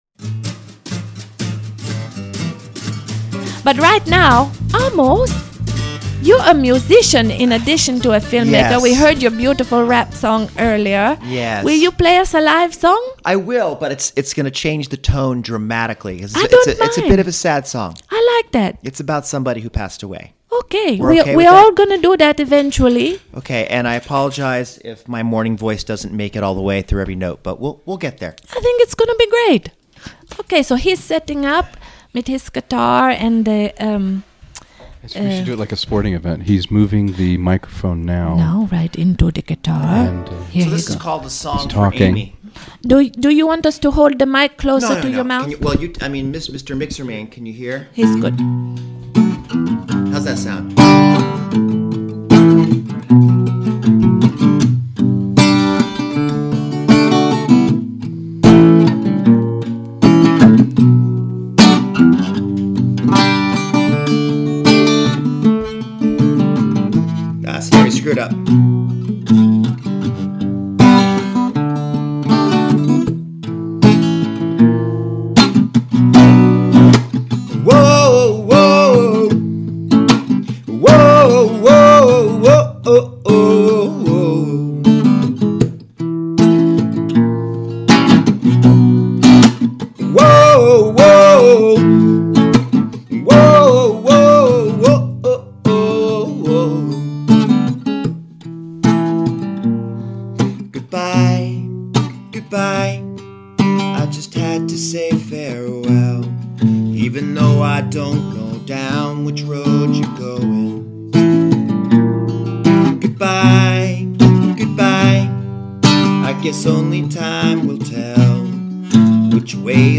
This original song is called "The Song for Amy." Recorded LIVE